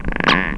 rocket_idle_chirp3.wav